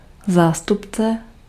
Ääntäminen
Synonyymit zastupitel Ääntäminen Tuntematon aksentti: IPA: /zaːstʊpt͡sɛ/ Haettu sana löytyi näillä lähdekielillä: tšekki Käännös Ääninäyte Substantiivit 1. proxy US 2. representative US 3. agent US Suku: m .